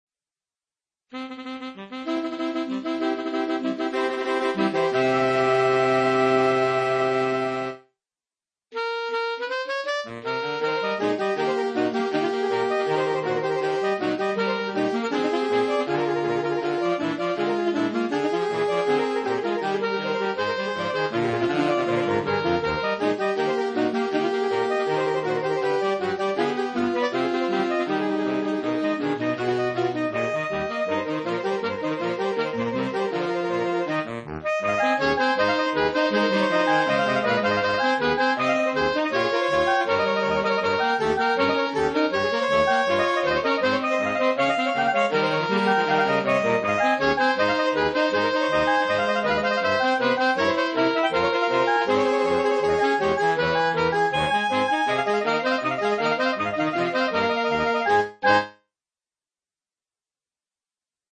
Voicing: Saxophone 4tet